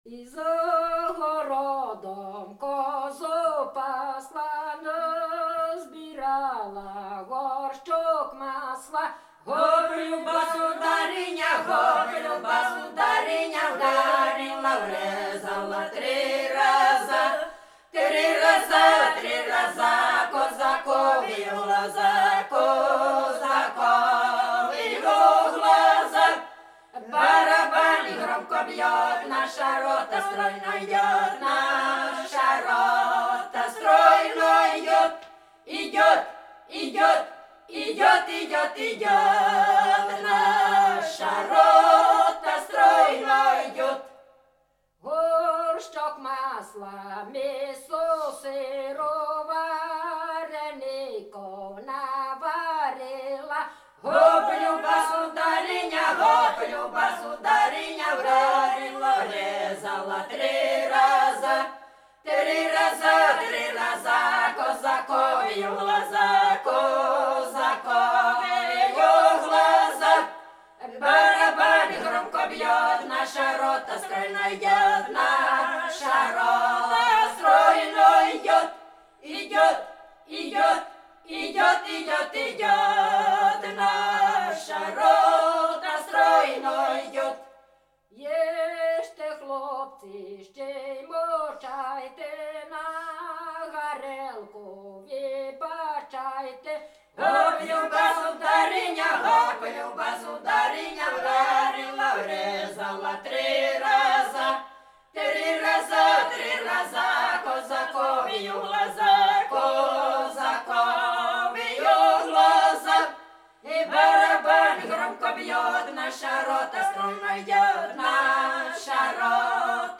Ну і родзинка рекрутсько-солдатських пісень — стройові — унікальні зразки із абсолютно побутовим сюжетом у куплетах і маршовим приспівом, з текстом, пов’язаним зі службою у війську.
Ці зразки музично відрізняються від інших підкресленою акцентною ритмікою, маршевістю, часто насичені російською лексикою, що дозволяє припустити про їх походження саме з солдатського середовища і прикладне використання для організації спільної синхронної ходи «в ногу».
Стройова з села Сарновичі Коростенського району Житомирської області.